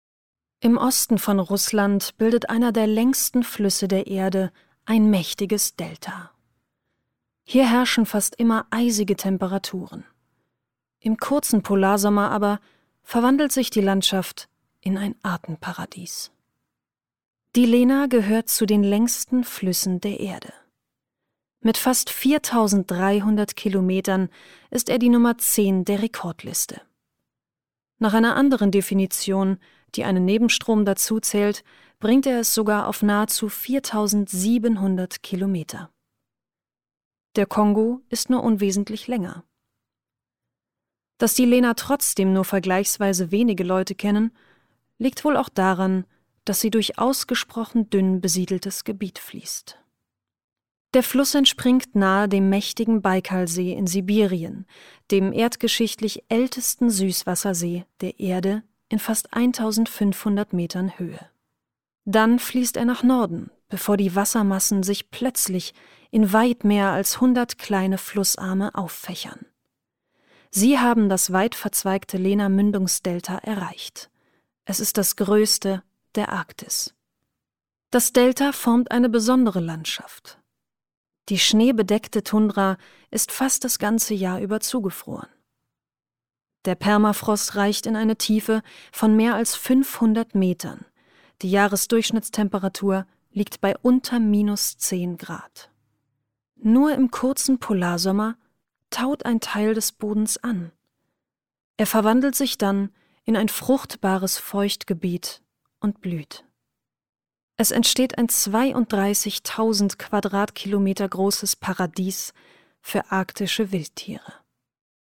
Naturdokumentation